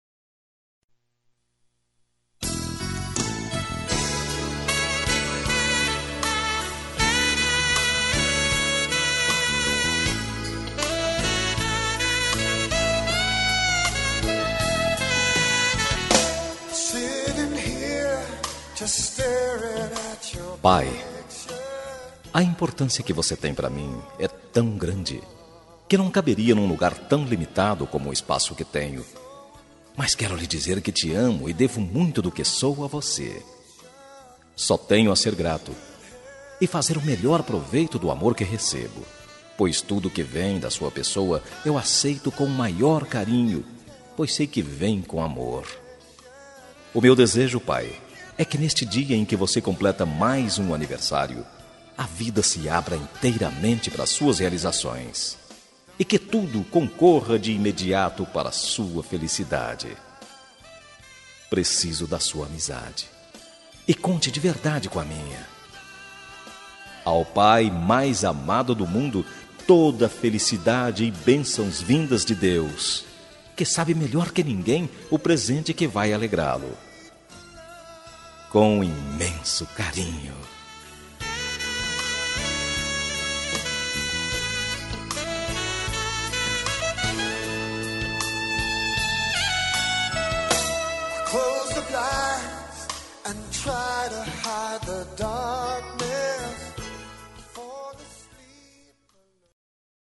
Aniversário de Pai – Voz Masculina – Cód: 11604